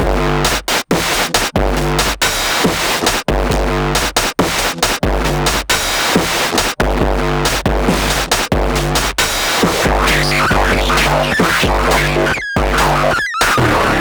Distorted drumness
Just cut up a clip from a session
myself drum noodling & his eurorack awhile back. dist drum 8 bars 137 bpm PlayStop
dist-drum-8-bars-137-bpm.wav